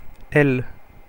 Ääntäminen
Ääntäminen France (Paris): IPA: [ɛl] Tuntematon aksentti: IPA: /l/ IPA: /j/ Haettu sana löytyi näillä lähdekielillä: ranska Käännöksiä ei löytynyt valitulle kohdekielelle.